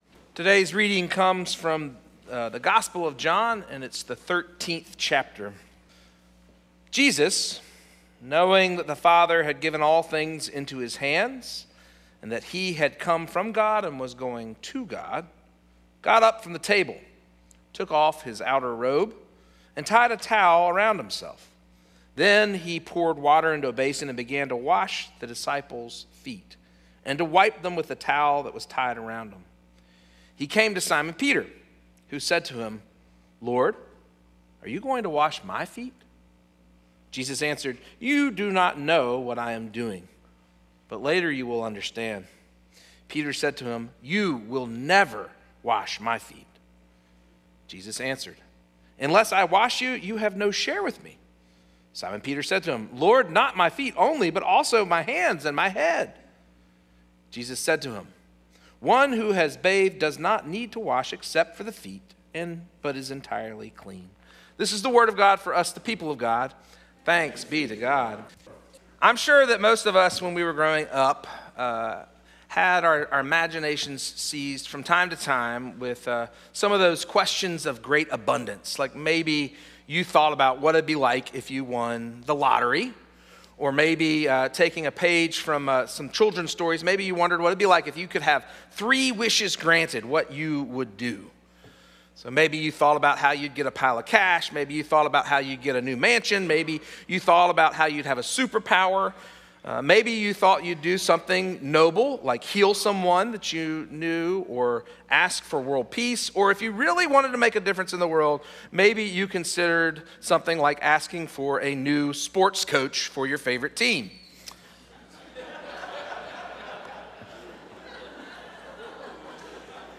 “humbled” Sermon Series, Week 4